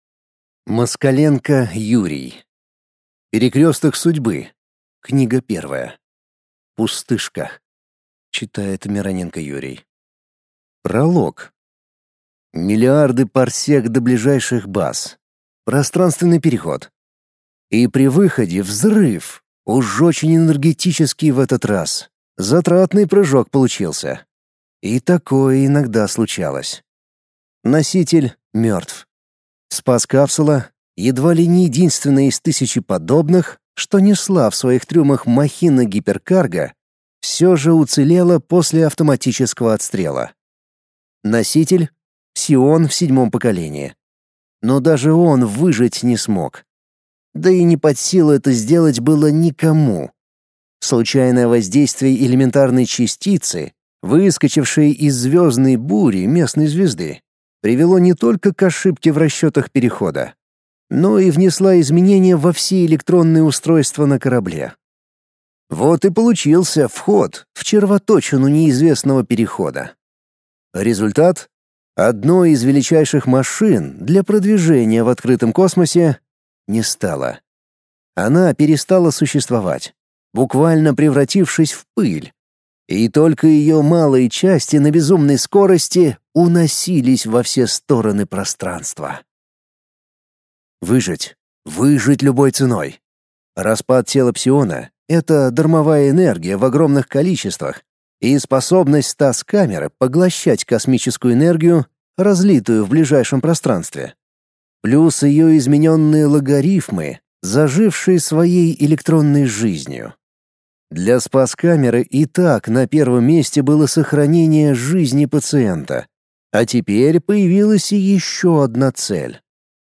Аудиокнига Пустышка | Библиотека аудиокниг